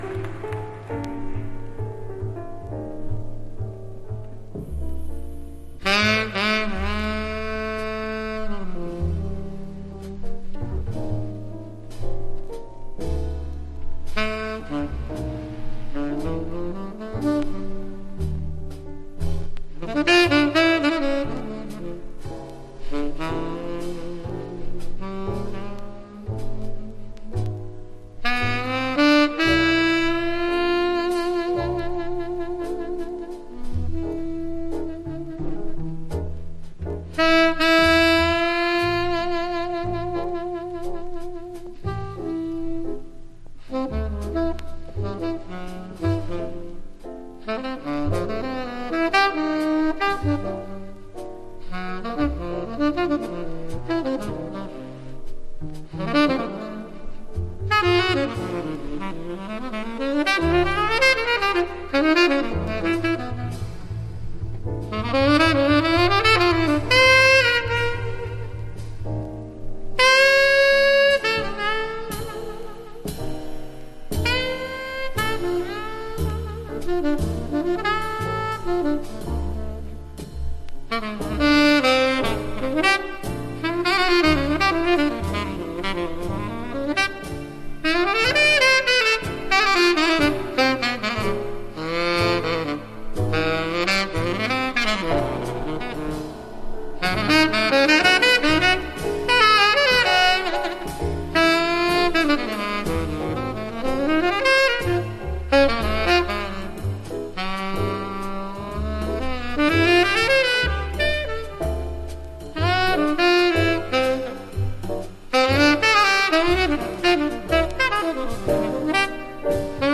（盤反り軽くありますが音に影響なし）
Genre US JAZZ